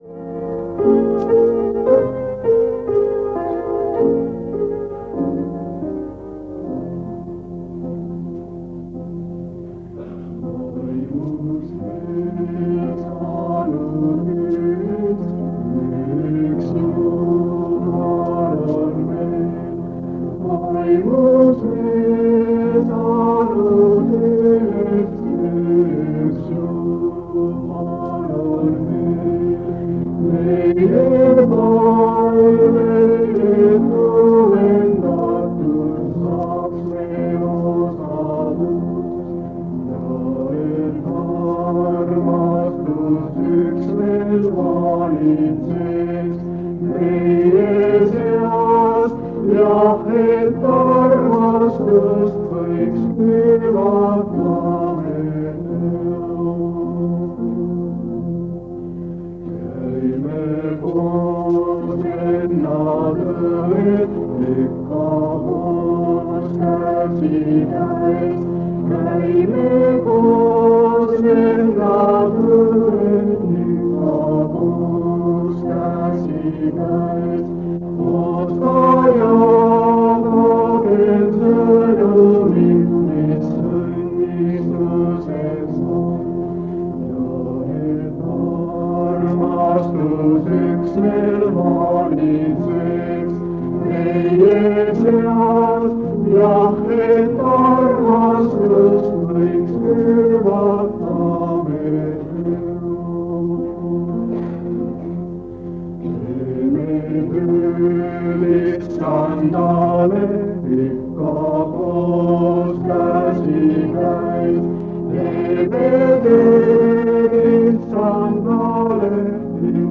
Jutlused
Koosolek vanalt lintmaki lindilt aastast 1975. Teemaks on Taanieli raamatu sündmused.